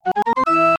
notif_song.mp3